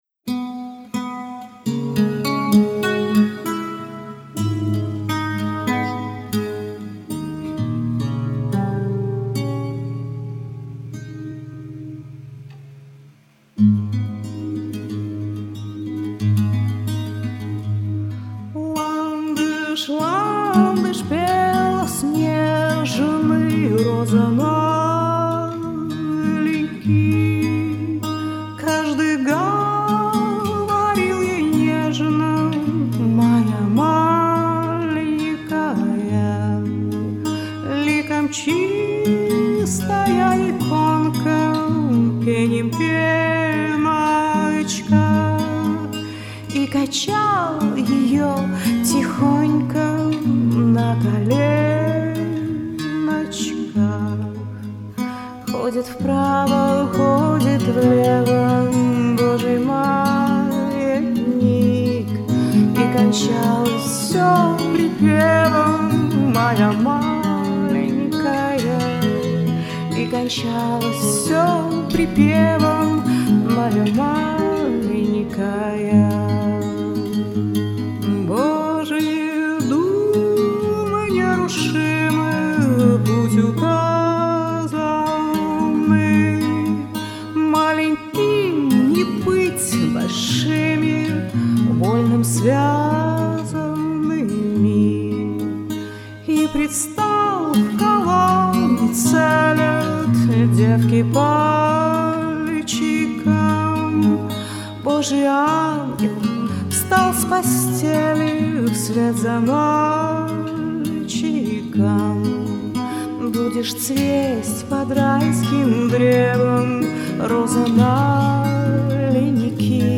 Эстрадный романс , в эстрадной манере.